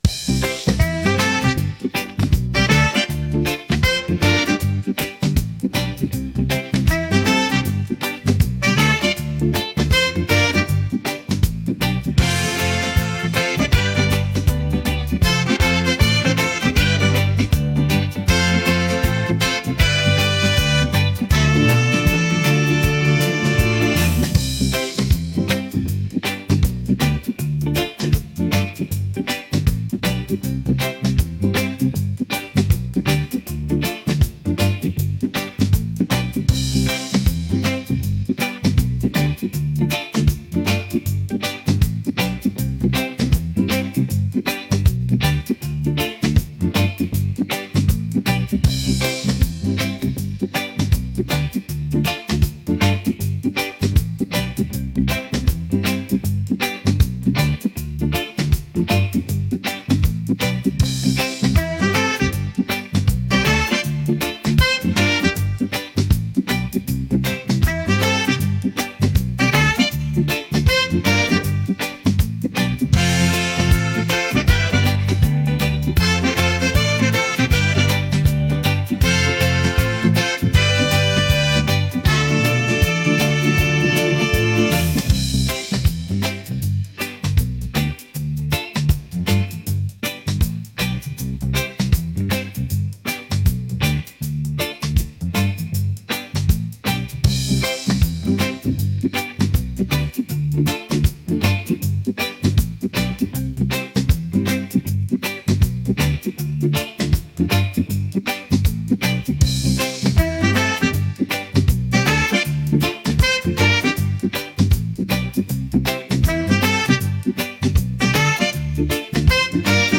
energetic | reggae | upbeat